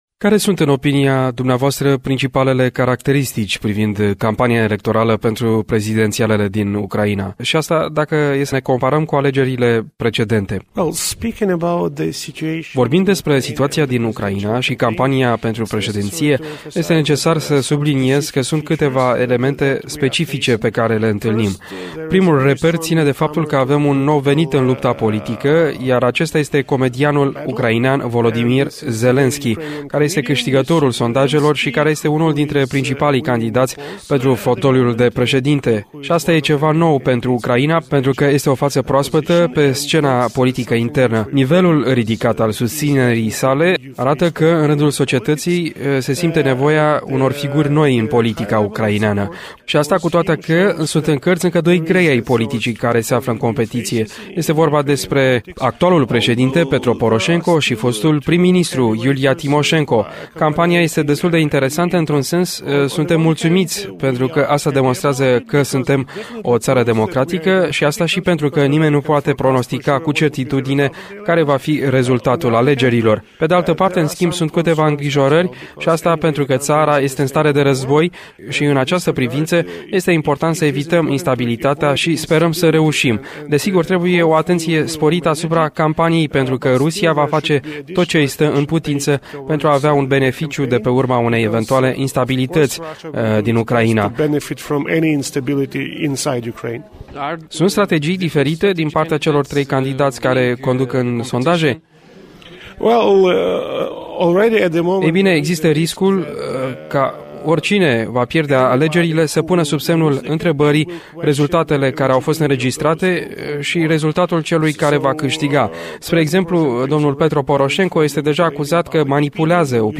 Evenimentul de la Iași a fost organizat în cadrul primei ediții a Forumului Româno – Ucrainean al Societății Civile pentru Dialog și Cooperare.